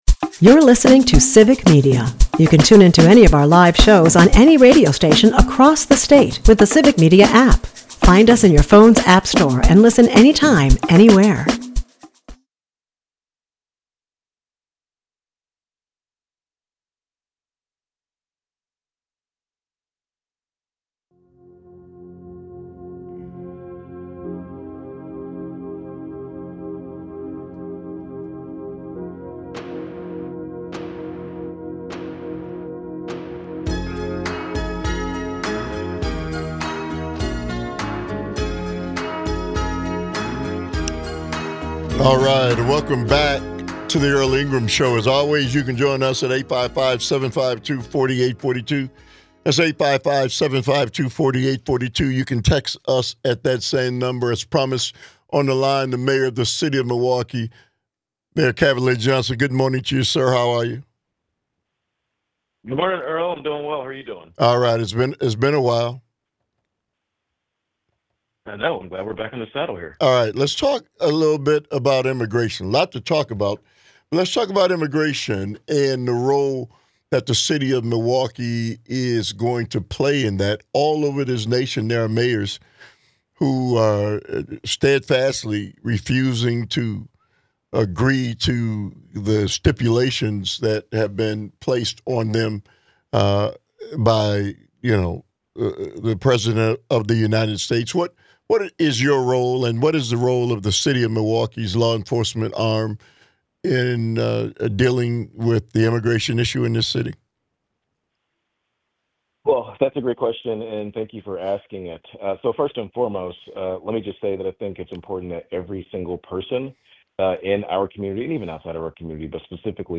Broadcasts live 8 - 10am weekdays across Wisconsin.